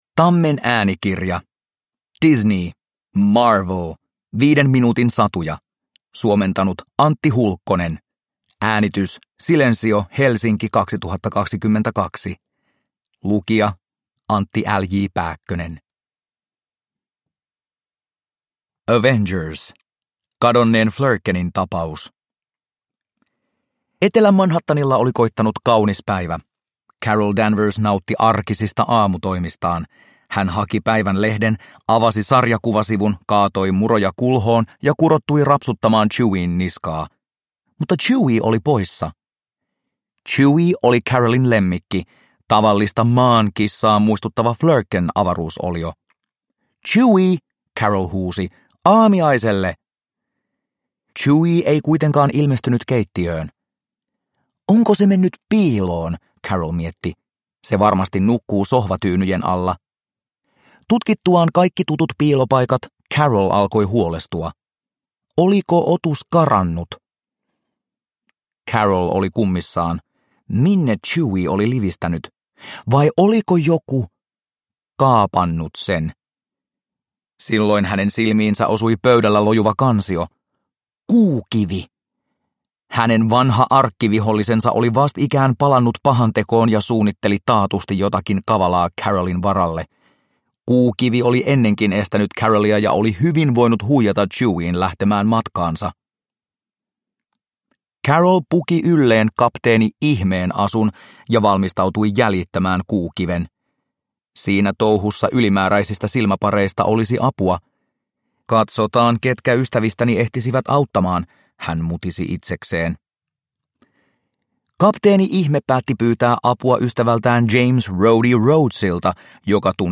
Marvel 5 minuutin satuja – Ljudbok – Laddas ner